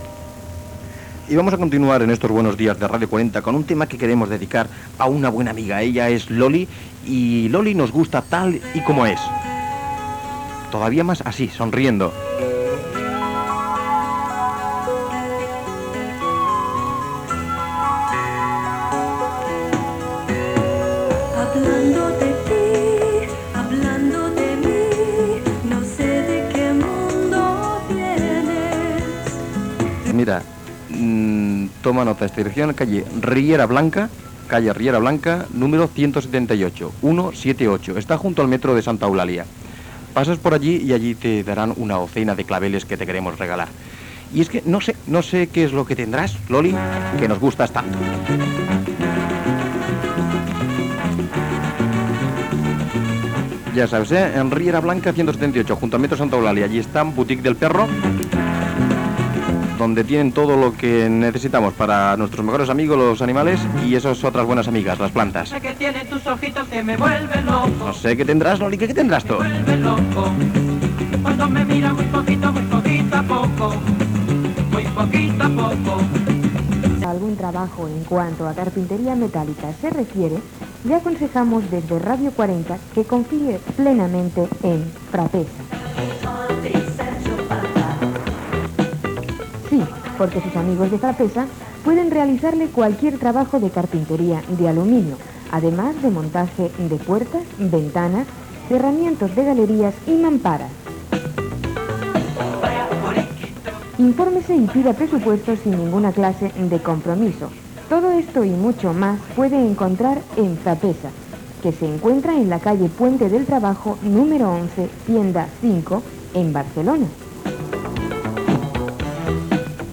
Tema musical dedicat, publicitat, identificació, "Radio 40 Informativo": crèdit dels EEUU a països d'Amèrica llatina.
Informatiu
FM